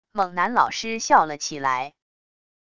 猛・男老师笑了起来wav音频